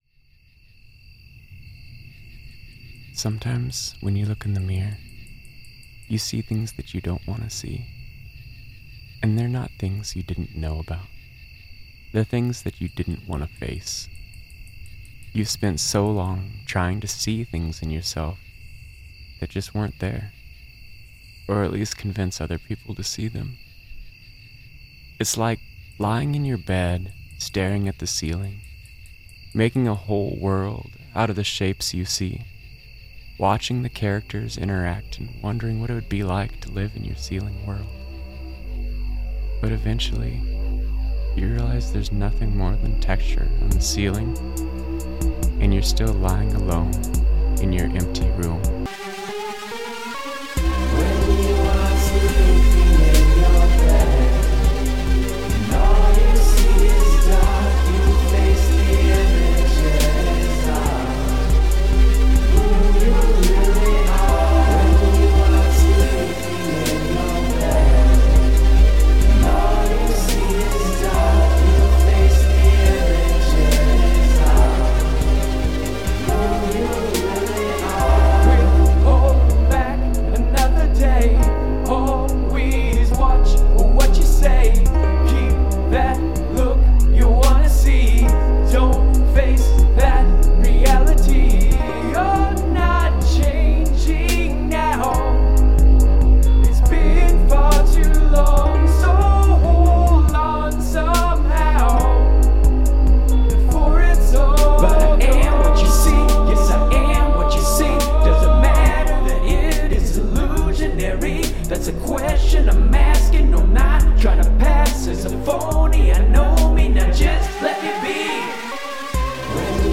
Use of field recording
Your field recording works as an intro/outro.